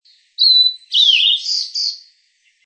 16-2阿里山藪鳥duet2.mp3
黃痣藪鶥 Liocichla steerii
嘉義縣 阿里山 阿里山
錄音環境 森林
行為描述 二重唱